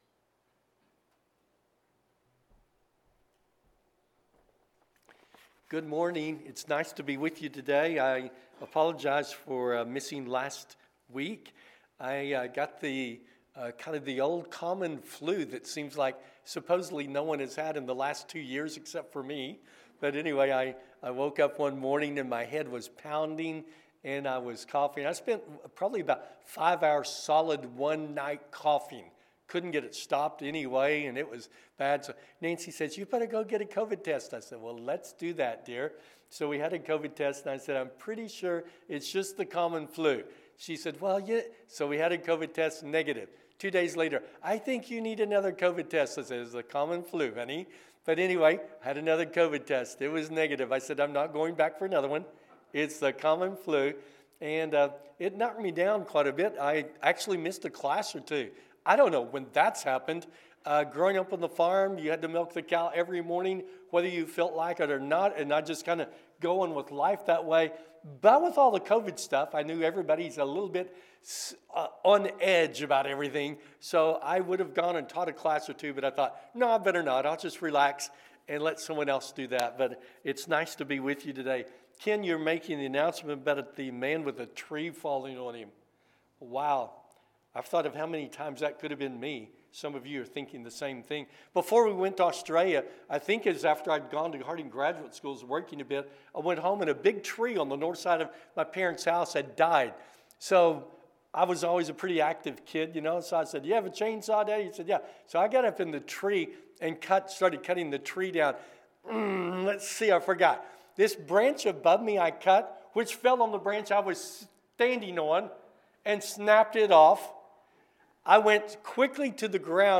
When Things Are Bad, Do Good – Sermon